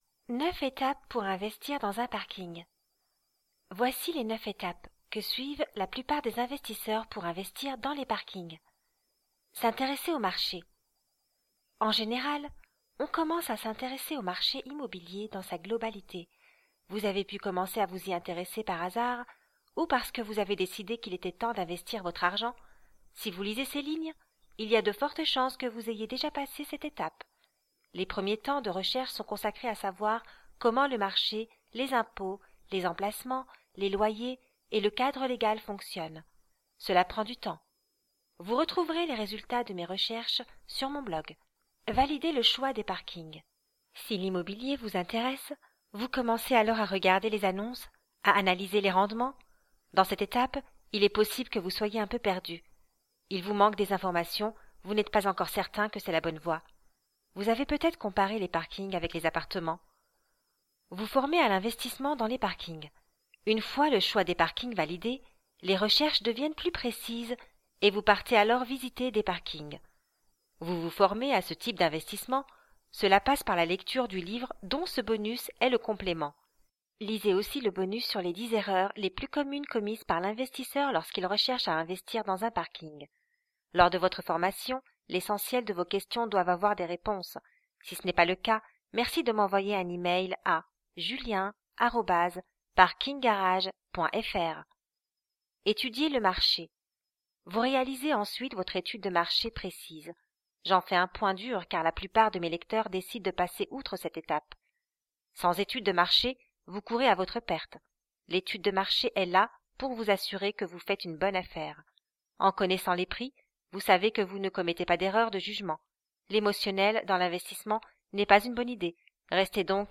Voix off française féminine institutionnelle, calme pour des spots publicitaires ou narration.
Sprechprobe: Sonstiges (Muttersprache):
French voice over artist with a naturally warm, articulate and engaging voice, specializing in audio books, childrens narration.
I work from my home studio and can normally deliver within 24 hours.